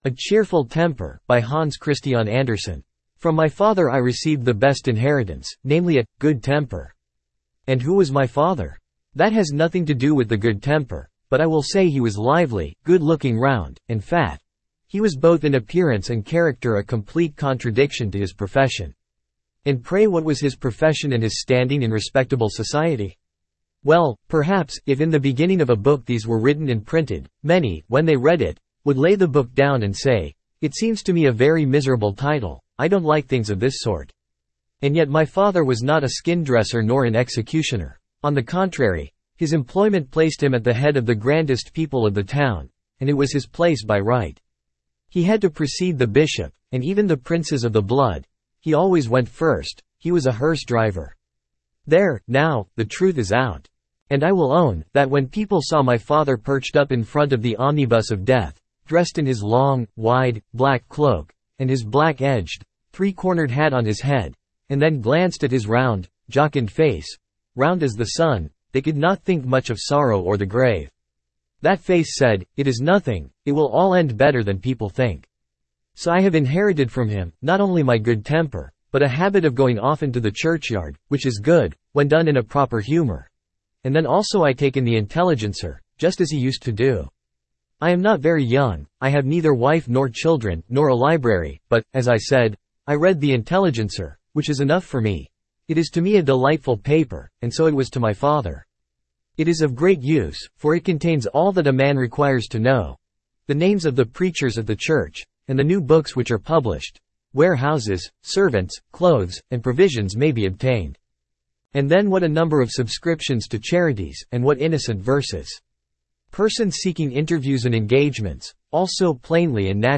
Standard (Male)